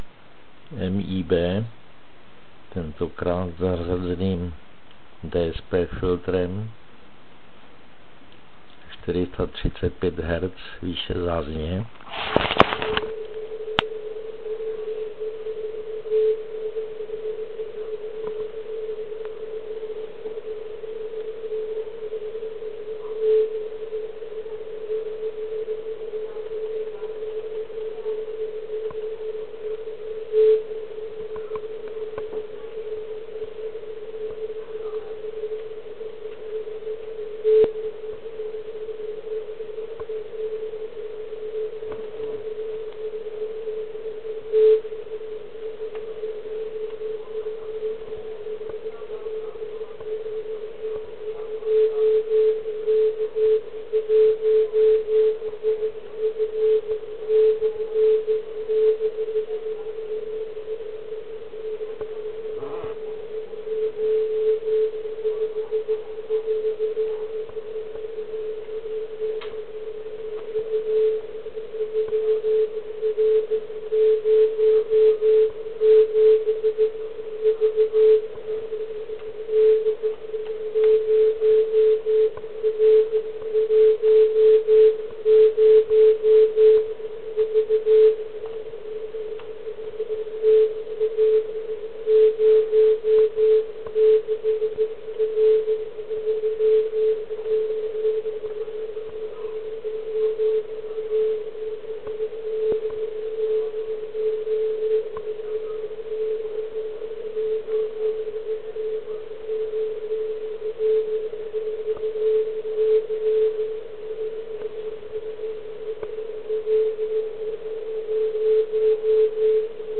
Pro Vaši orientaci uvádím, že se jedná o poslech majáku MIB (Micro Ionospheric Beacon). Ten pravidelně vysílá na kmitočtu 3579.74 kHz a slouží k předání WX informace z místa v Jizerských horách.
Nahrávka REC33 je dělána s použitým filtrem 300Hz a nahrávka REC34 je dělána s filtrem DSP označovaným jako APF (Audio Peak Filter).